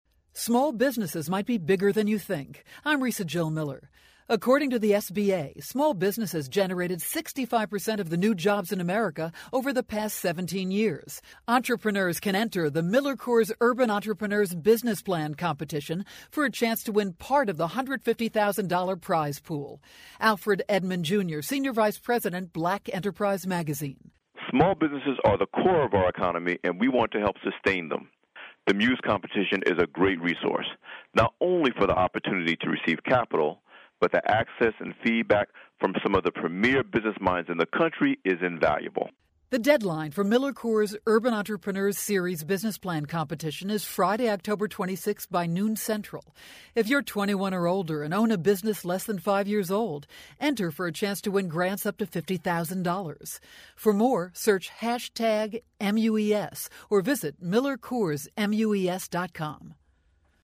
October 18, 2012Posted in: Audio News Release